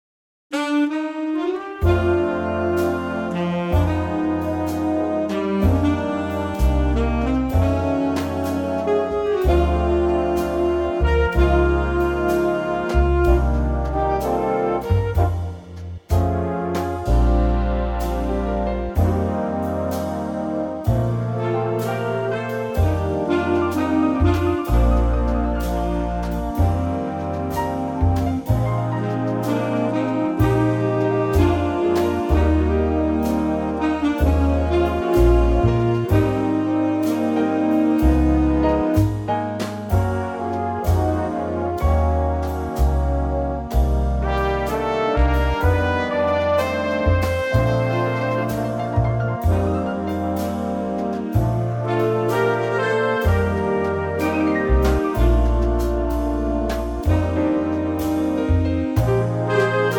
key - Bb - vocal range - Bb to C
in a mellow Big Band arrangement.